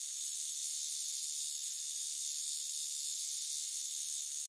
剃刀
描述：电动剃须刀。
Tag: 剃须 OWI Shav呃